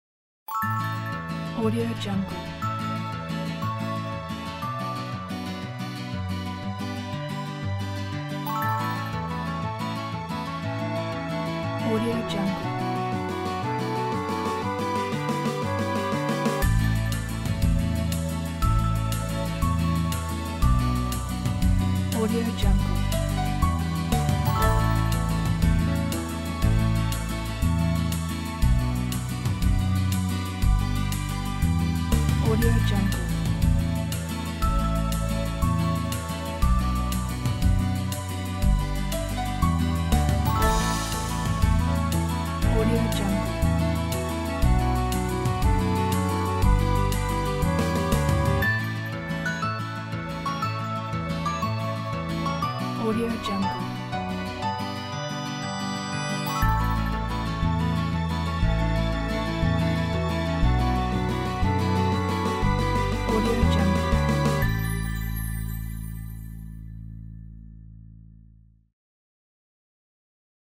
1970-01-01 欢快节奏